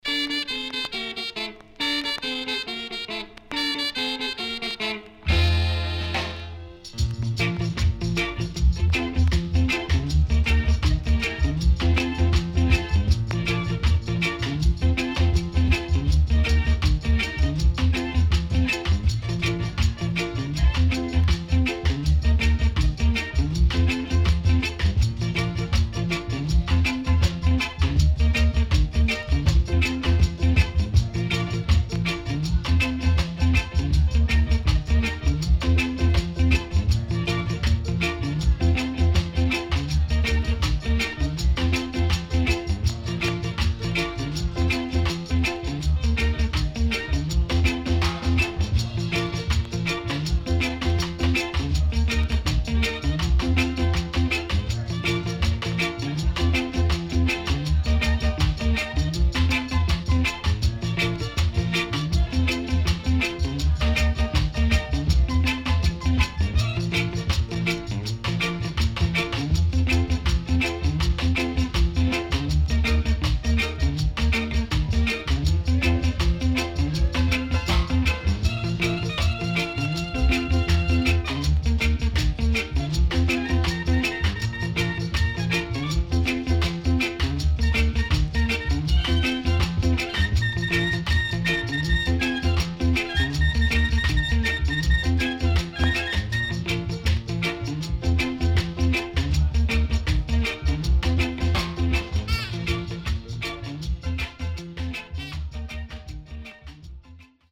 間奏のトランペットのソロも良いです。
SIDE A:所々チリノイズがあり、少しプチノイズ入ります。